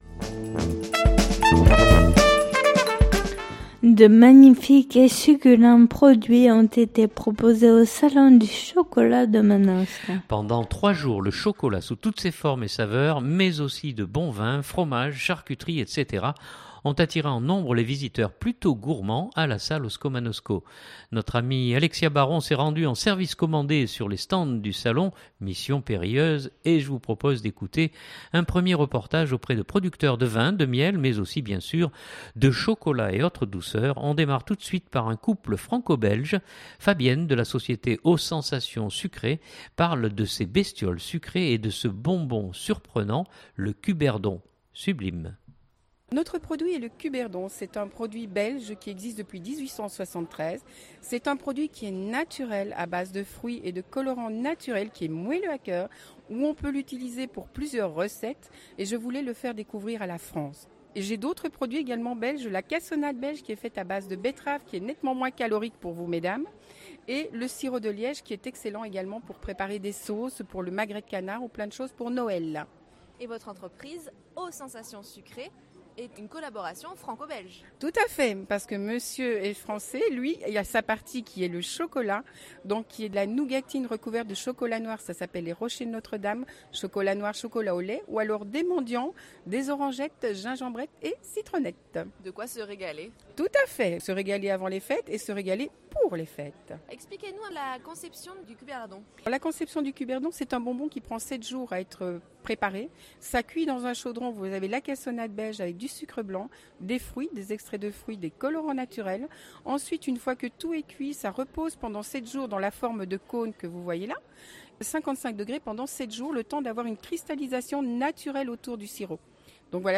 Rencontre avec des exposants au Salon du Chocolat de Manosque